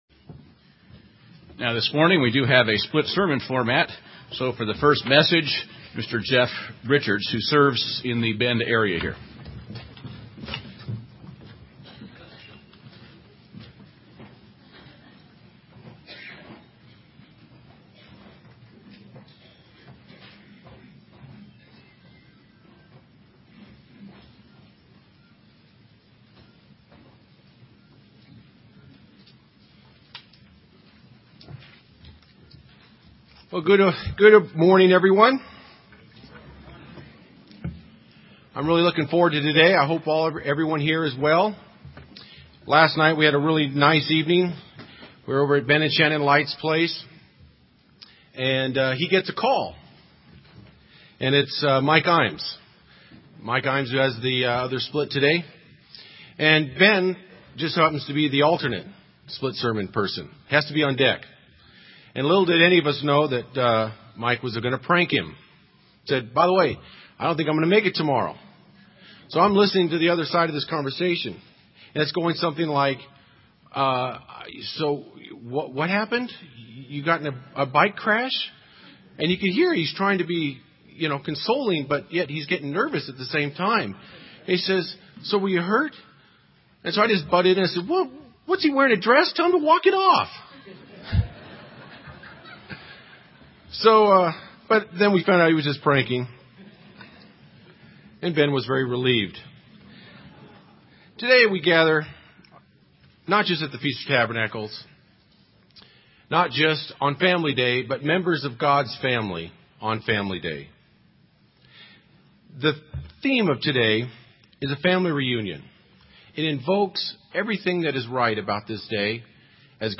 This sermon was given at the Bend, Oregon 2012 Feast site.